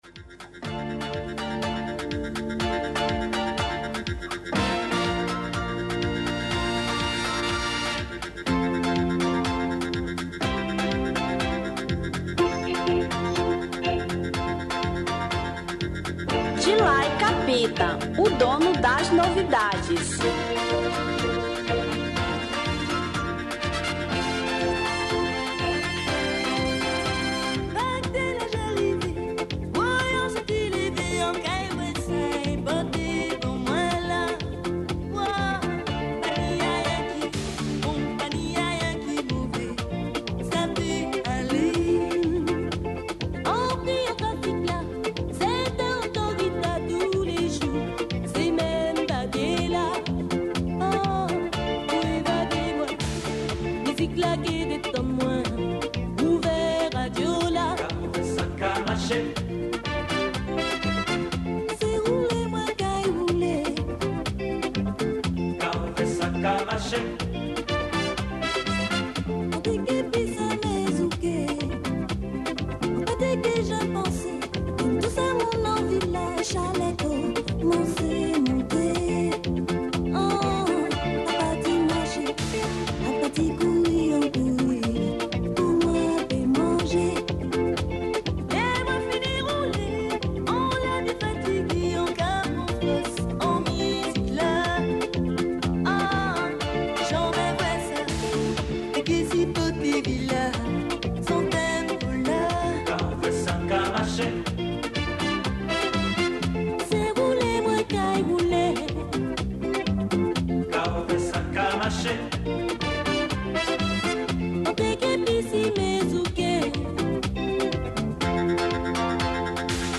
Zouk 1989